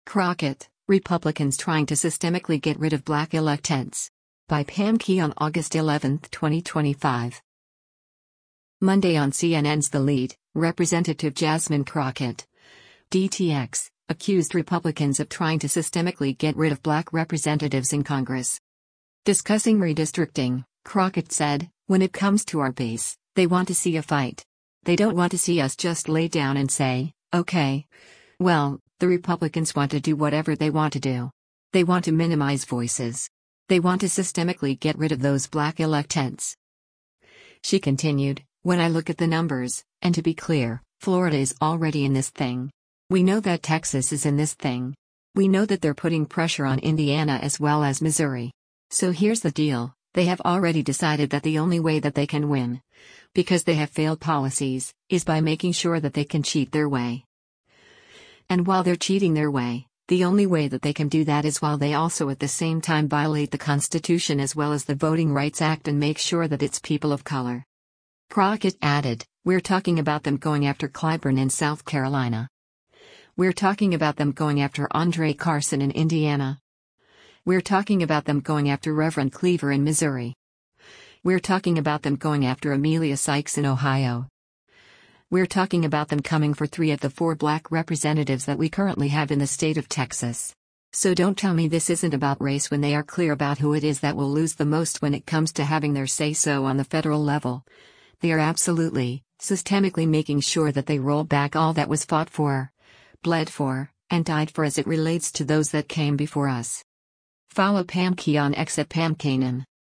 Monday on CNN’s “The Lead,” Rep. Jasmine Crockett (D-TX) accused Republicans of trying to systemically “get rid” of black representatives in Congress.